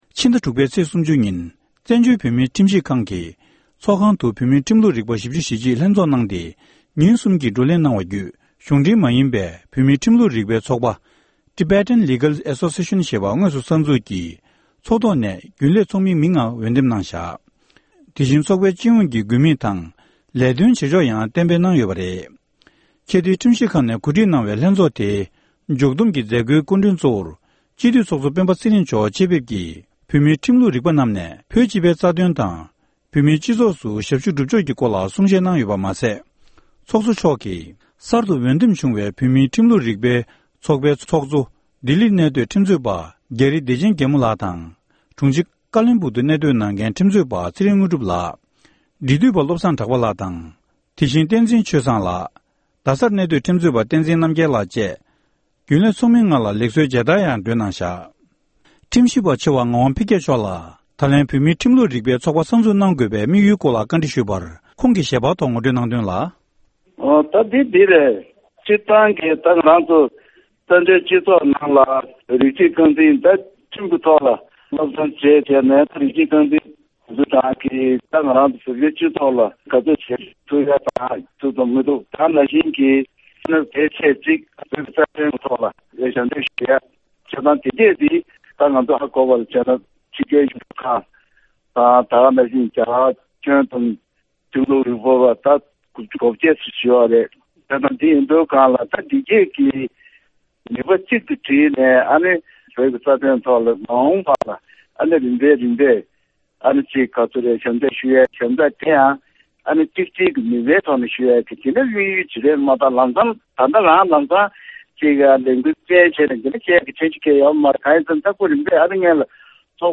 སྒྲ་ལྡན་གསར་འགྱུར། སྒྲ་ཕབ་ལེན།
གསར་འགྱུར་དཔྱད་གཏམ།